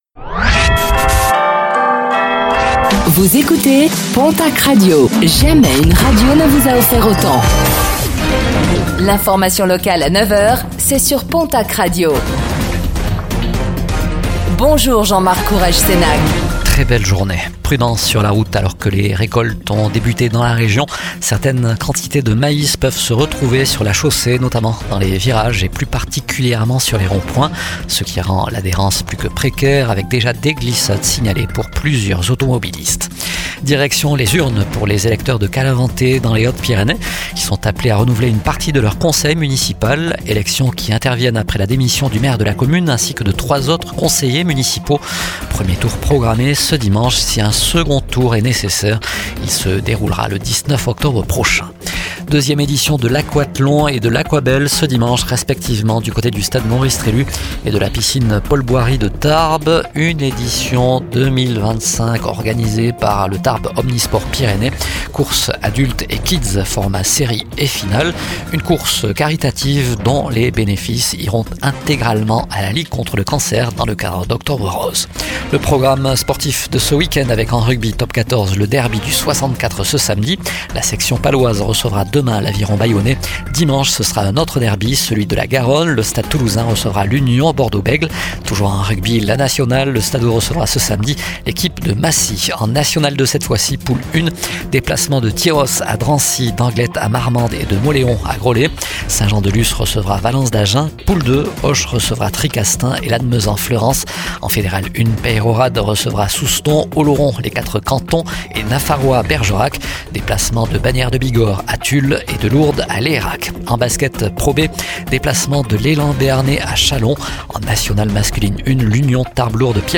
Réécoutez le flash d'information locale de ce vendredi 10 octobre 2025